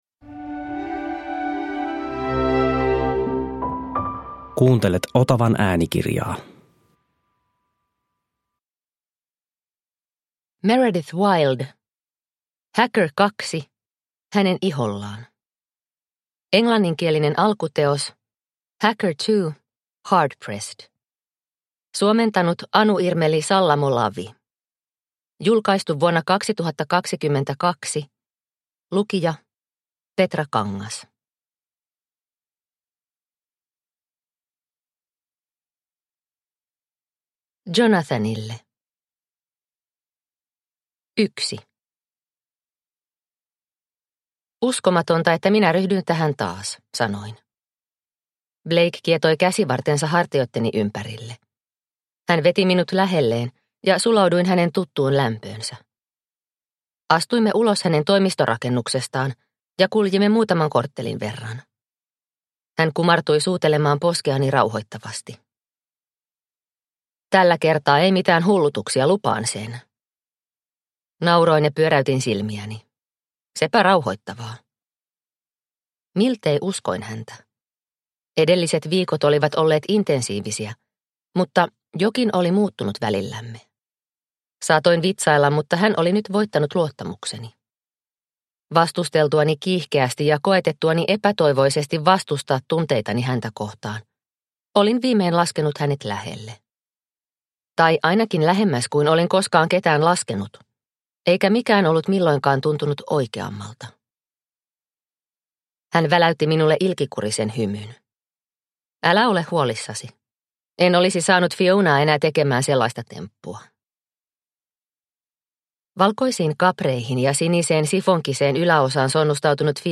Hacker 2. Hänen ihollaan – Ljudbok – Laddas ner